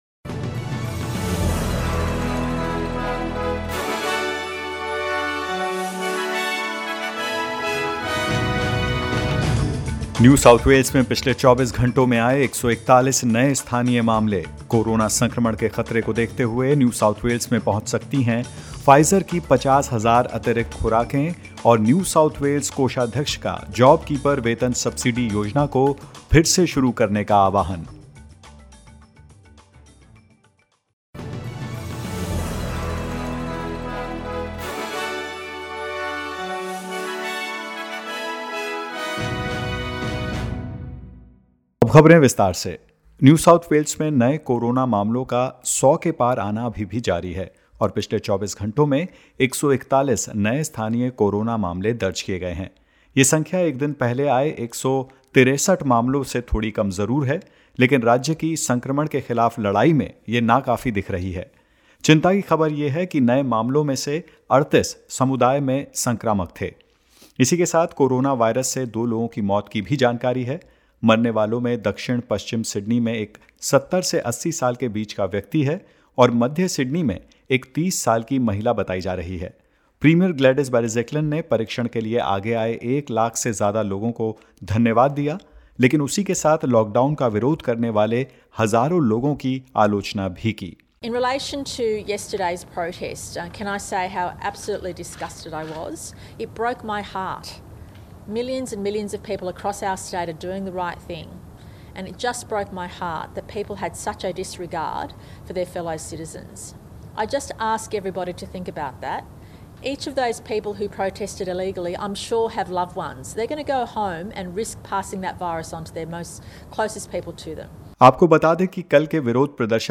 In this latest SBS Hindi News bulletin of Australia and India: All 11 new cases in Victoria are linked to the current outbreak; Federal government secures extra 85 million Pfizer COVID-19 doses and more.